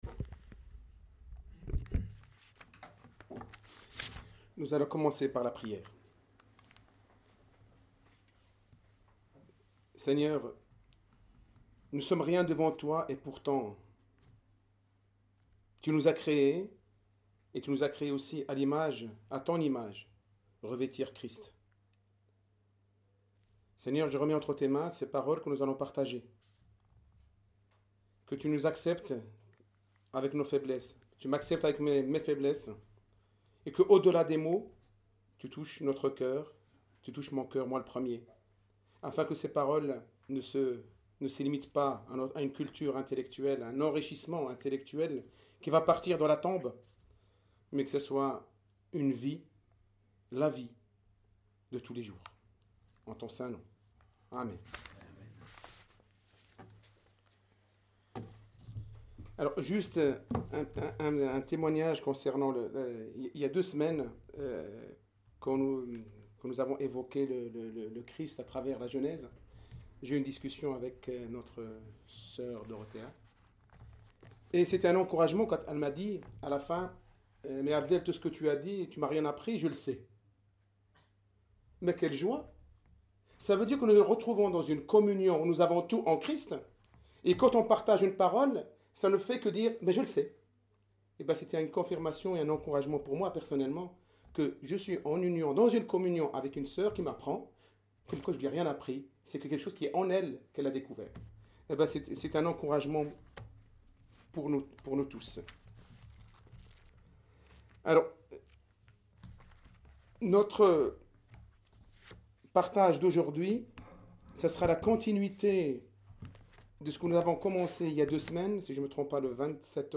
Prédicateurs